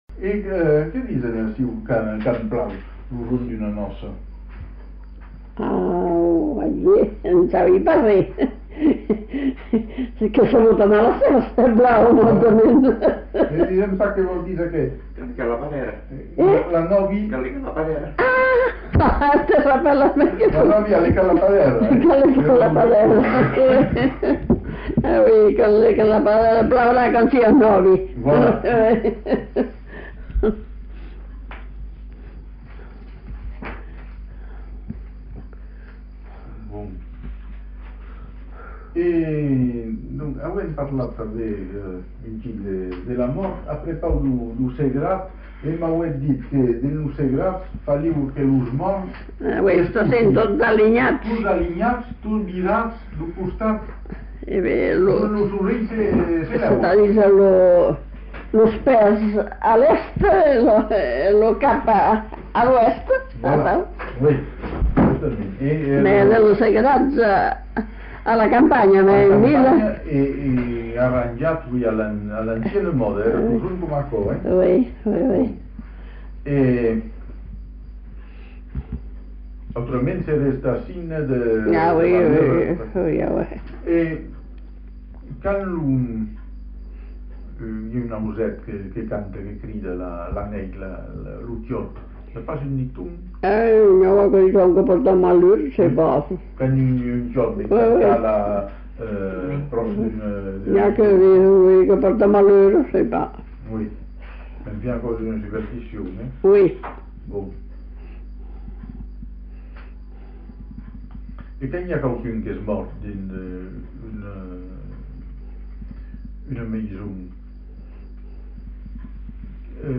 Lieu : Sauméjan
Genre : témoignage thématique